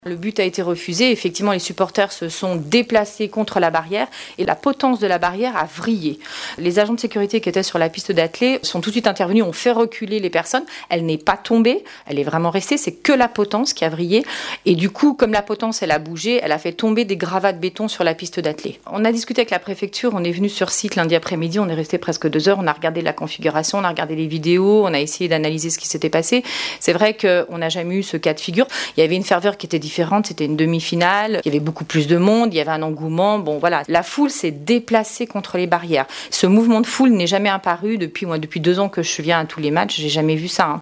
Que s’est-il passé dans le virage sud ? Précisions de Catherine Allard, Maire Adjointe déléguée aux sports.